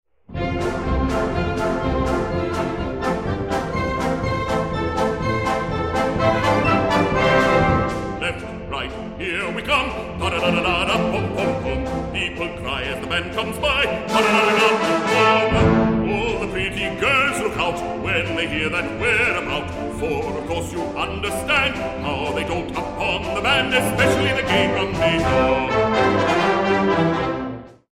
Bass Baritone